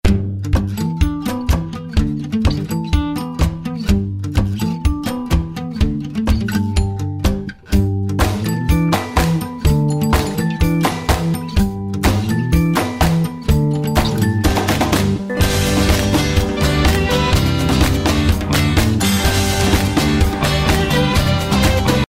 гитара
позитивные
без слов
инструментальные
колокольчики
позитивная музыка